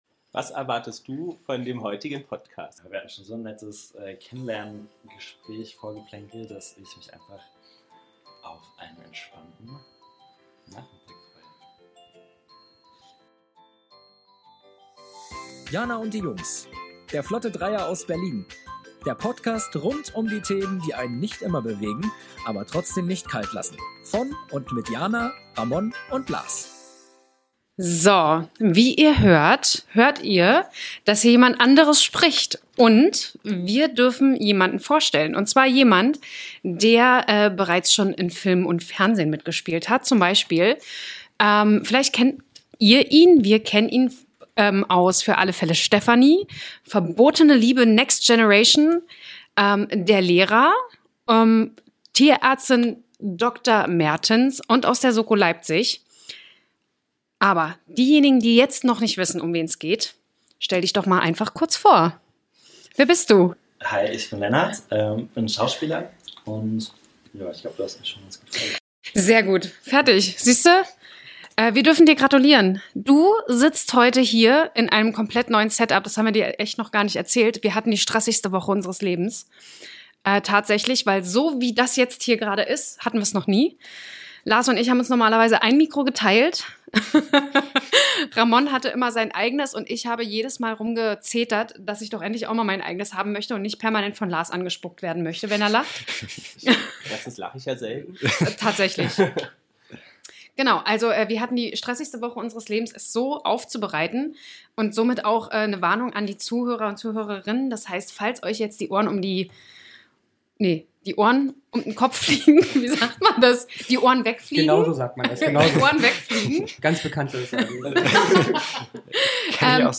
Wir hoffen, ihr hört den Qualitätsunterschied.